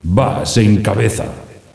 takenlead.ogg